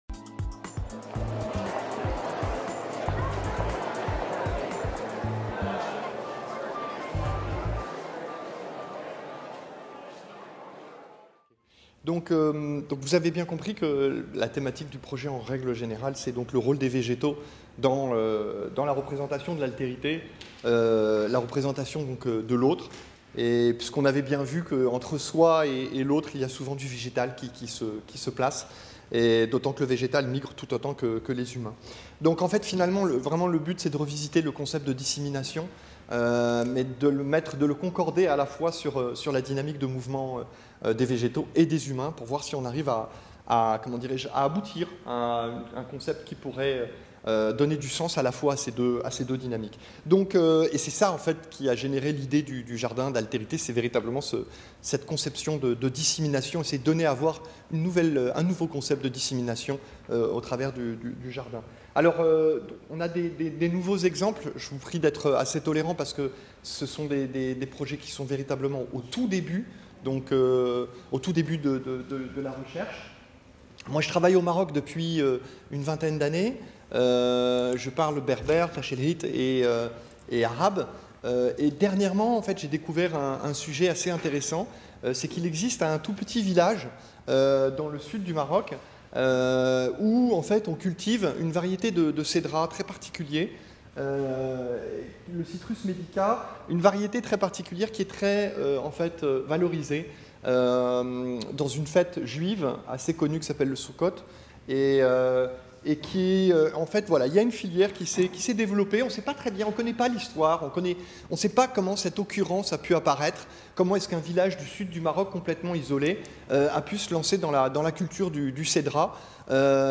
Workshop de présentation de l’équipe EXORIGINS
Jeudi 28 mars 2019, Jardin des Plantes, Paris